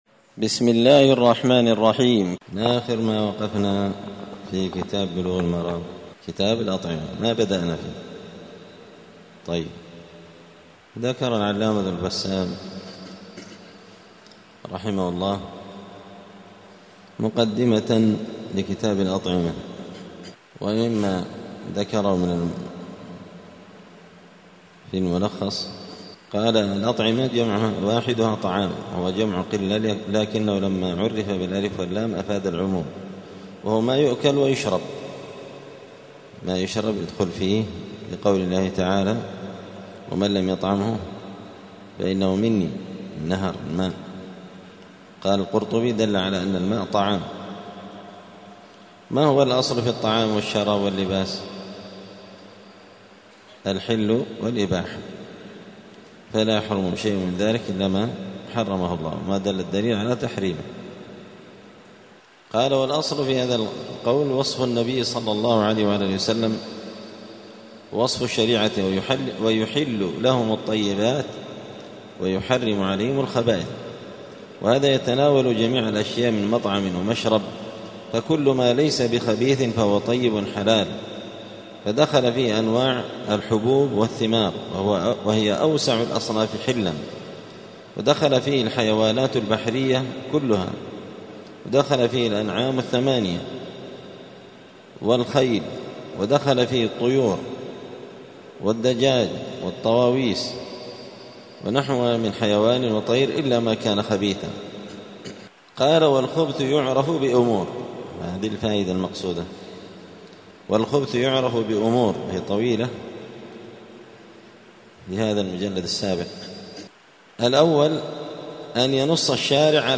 *الدرس الأول (1) {مقدمة كتاب الأطعمة وتعريفها}*
دار الحديث السلفية بمسجد الفرقان قشن المهرة اليمن